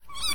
sounds / monsters / rat / death_2.ogg